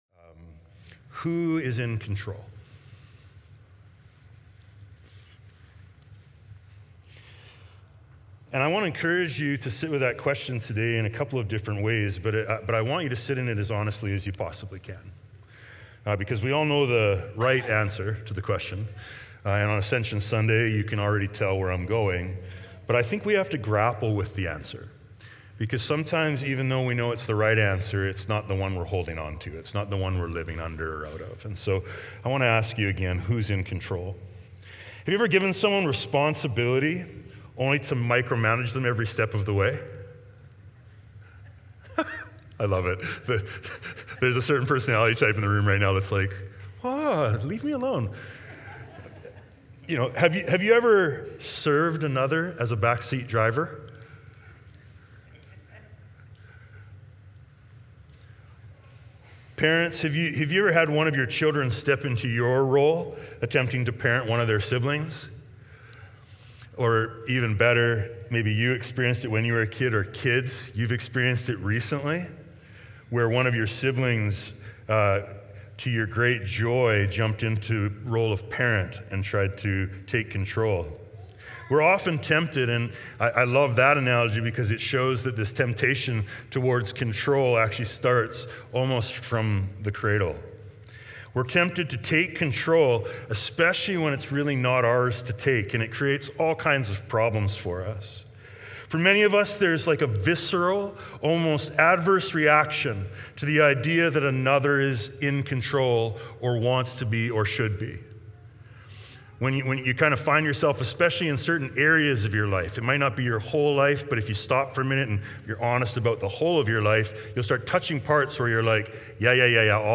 Sermons | Emmaus Road Anglican Church